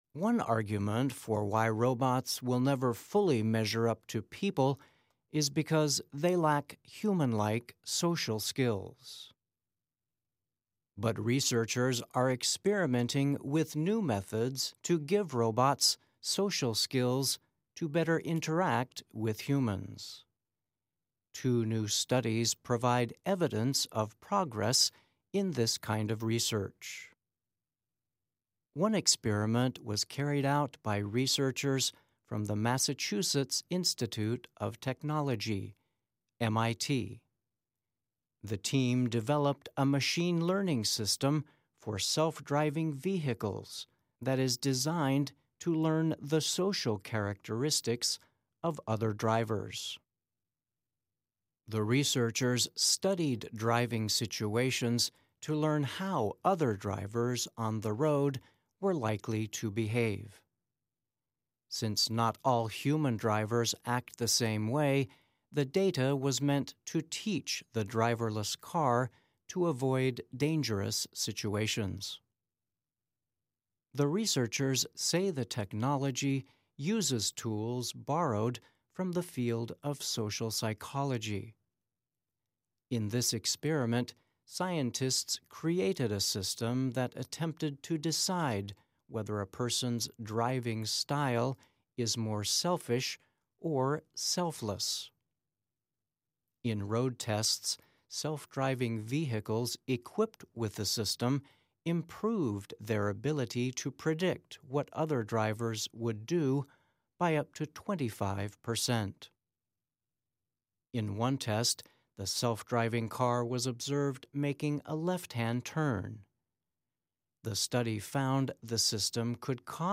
慢速英语:让机器人具备与人类相似的社交技能的研究